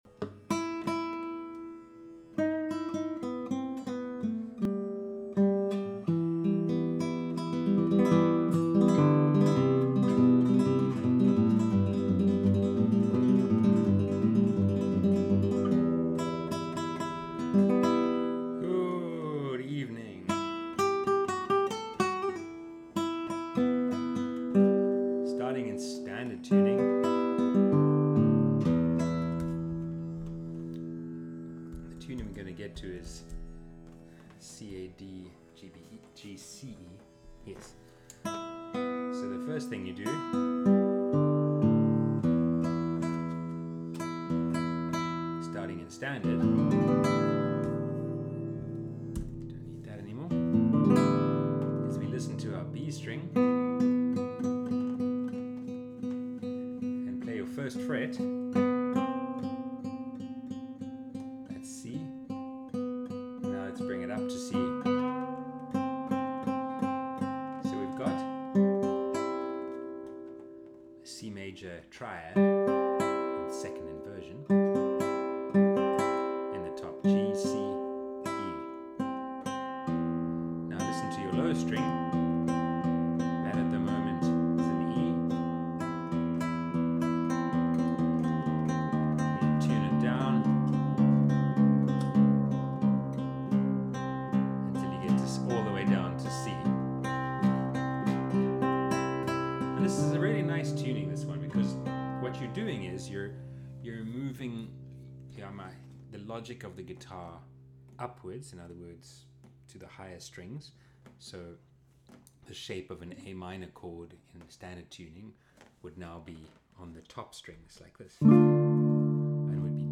Video lessons uploaded after every online group class.